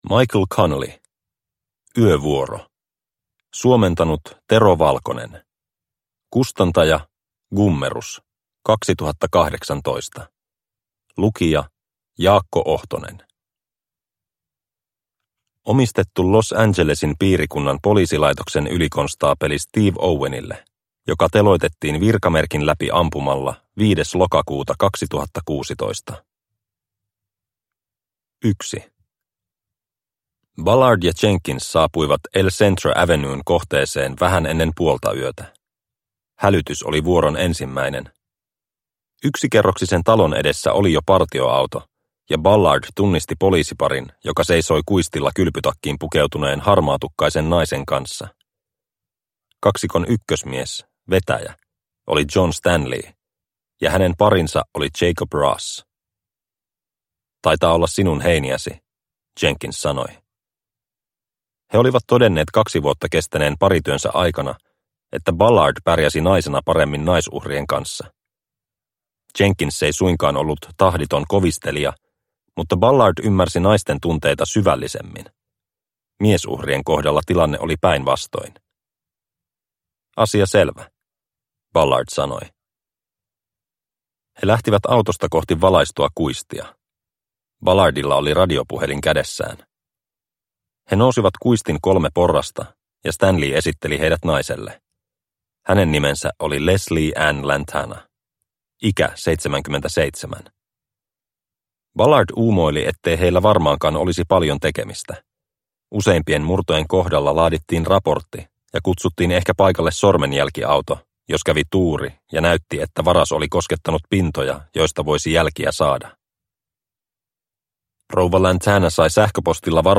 Yövuoro – Ljudbok